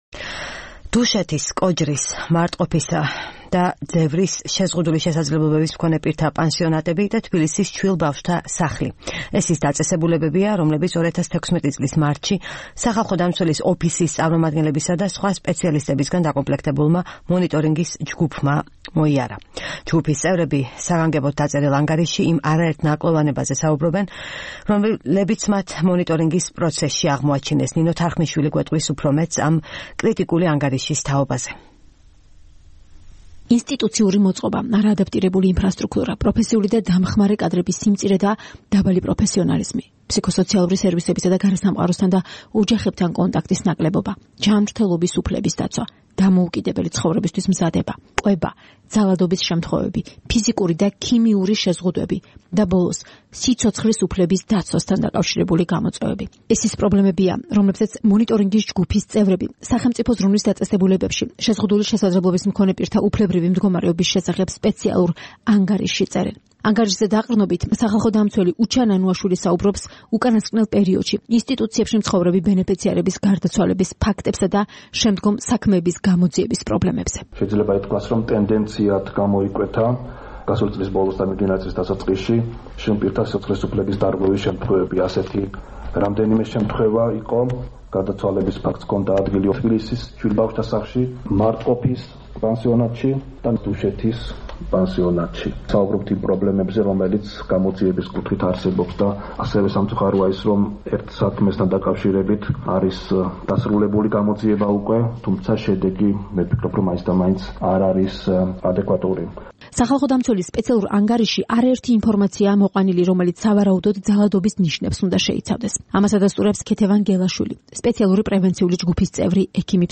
სახალხო დამცველის პრესკონფერენცია